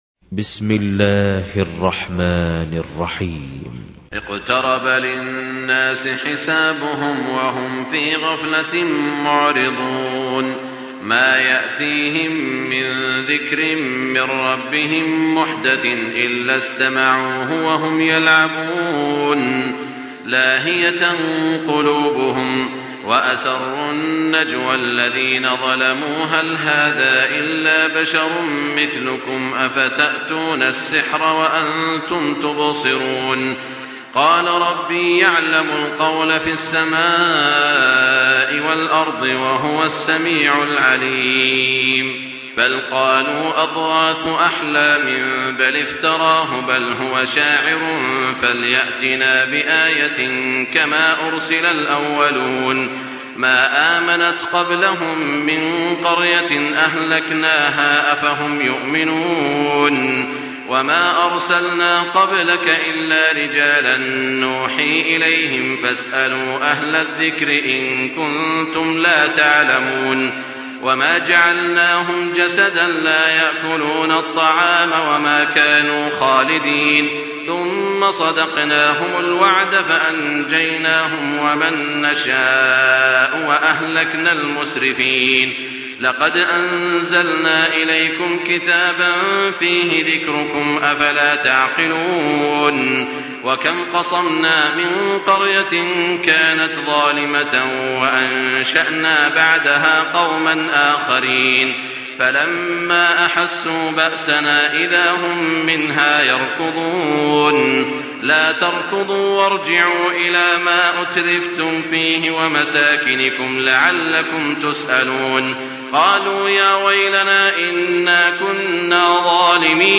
تراويح الليلة السادسة عشر رمضان 1424هـ سورة الأنبياء كاملة Taraweeh 16 st night Ramadan 1424H from Surah Al-Anbiyaa > تراويح الحرم المكي عام 1424 🕋 > التراويح - تلاوات الحرمين